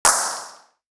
Band Noise Sizzle.wav